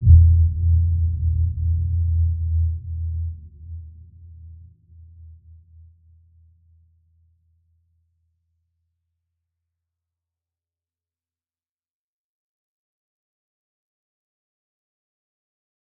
Dark-Soft-Impact-E2-mf.wav